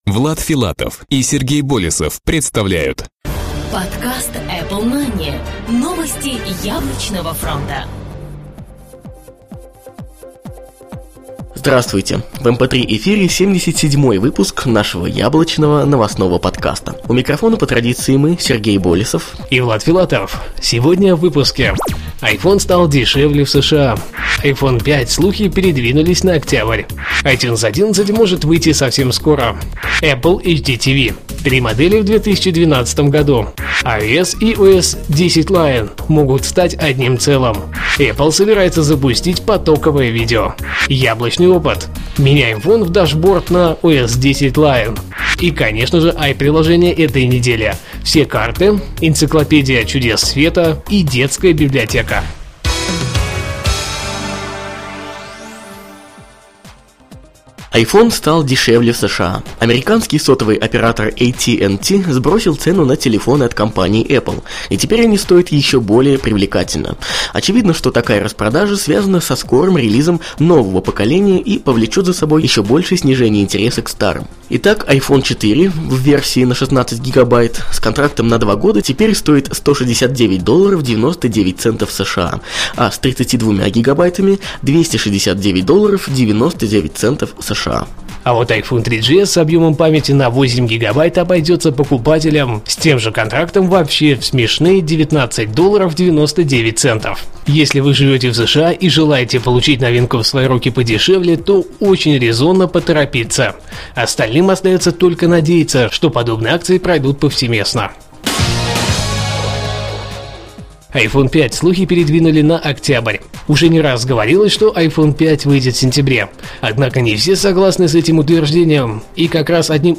Жанр: новостной Apple-podcast
stereo